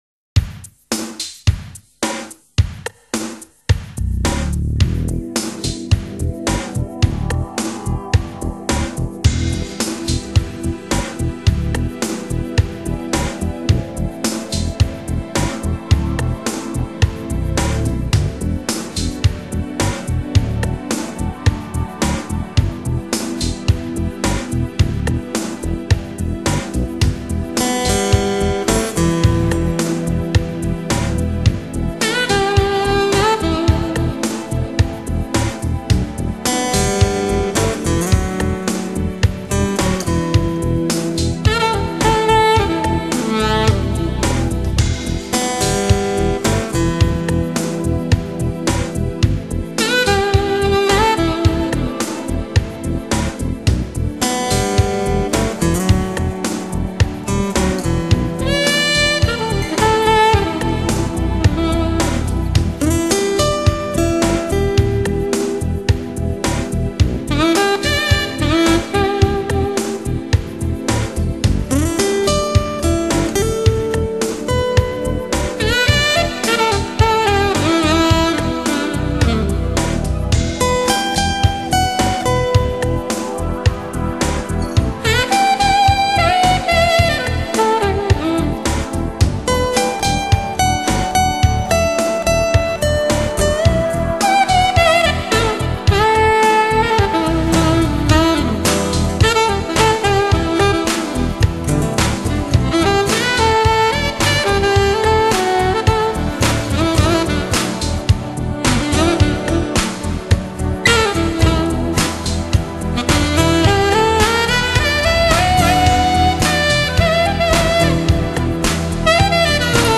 Genre: Jazz-Pop / Smooth Jazz